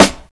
Snare (38).wav